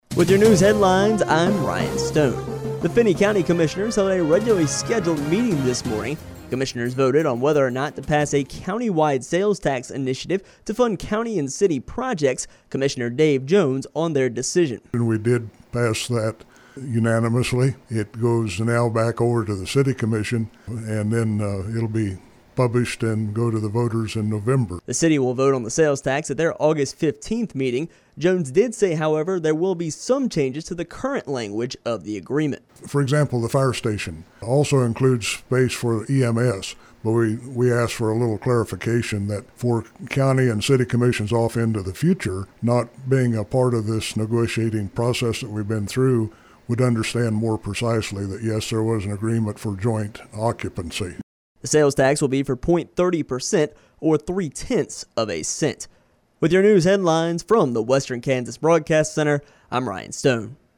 Radio Story